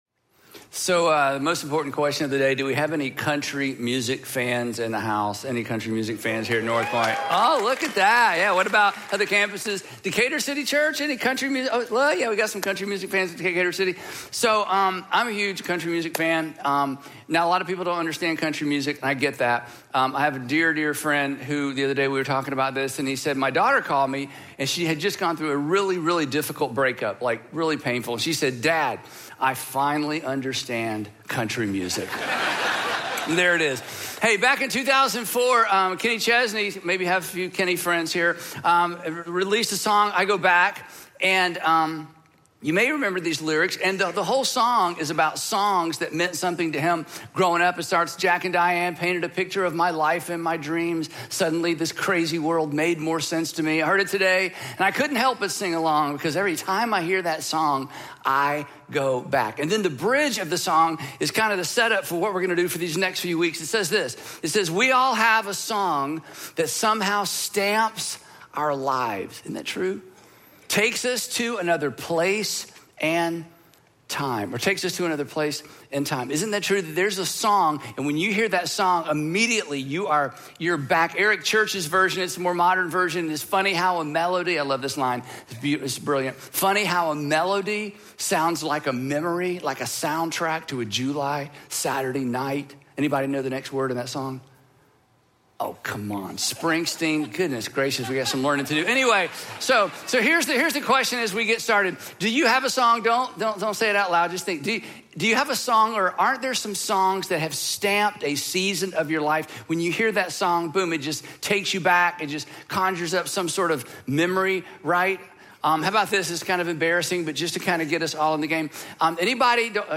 About This Message Andy Stanley - Jun 22, 2025 Message Only Music and Message When we see God for who he is, we’re able to trust him even deeper.